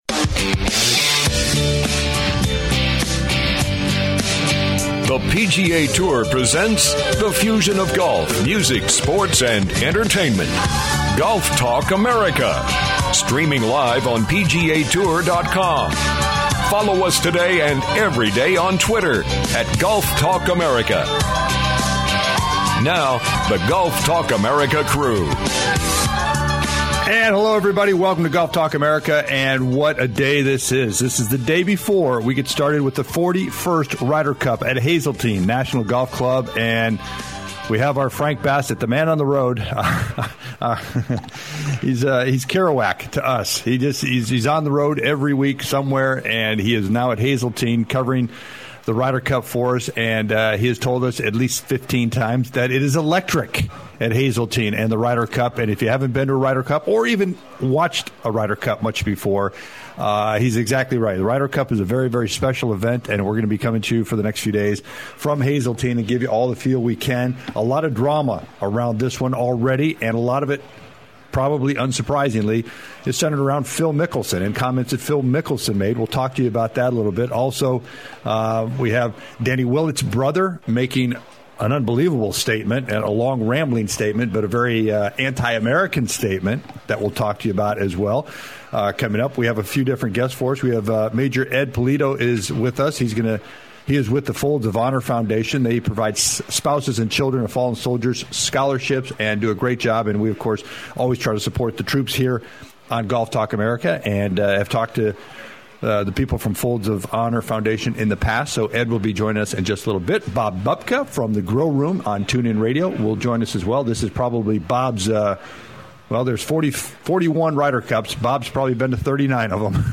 "LIVE" from The 41st Ryder Cup